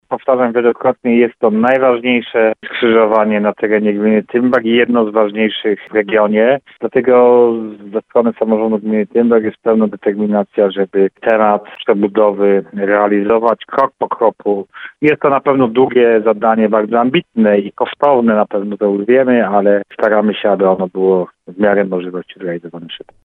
- Prace powinny skończyć się jesienią - mówi wójt Paweł Ptaszek.